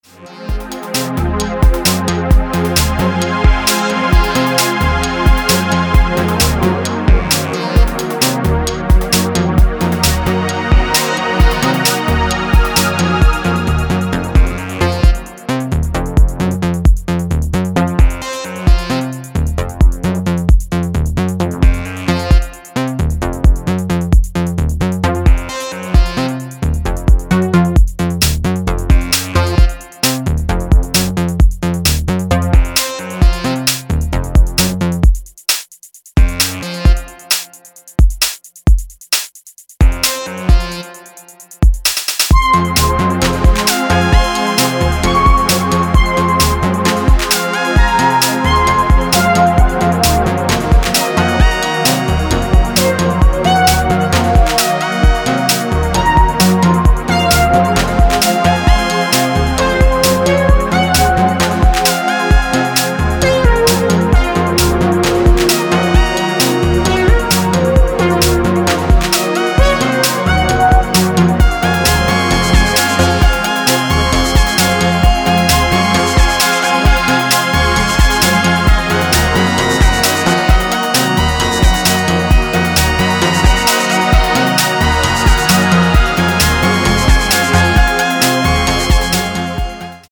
Electronix Techno Ambient